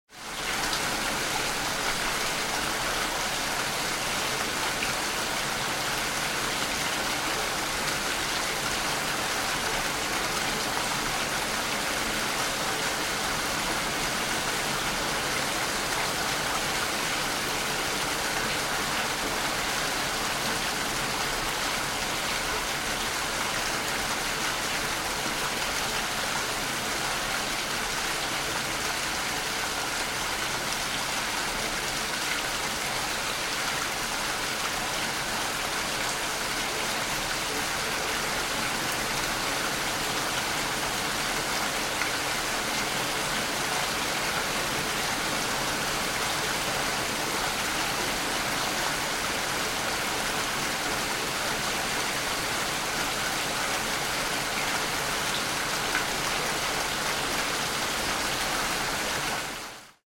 دانلود آهنگ باران 2 از افکت صوتی طبیعت و محیط
جلوه های صوتی
دانلود صدای باران 2 از ساعد نیوز با لینک مستقیم و کیفیت بالا
برچسب: دانلود آهنگ های افکت صوتی طبیعت و محیط دانلود آلبوم صدای نم نم باران از افکت صوتی طبیعت و محیط